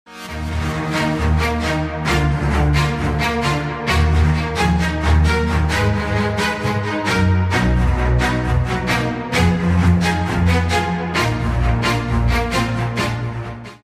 • Качество: 128, Stereo
громкие
без слов
скрипка
оркестр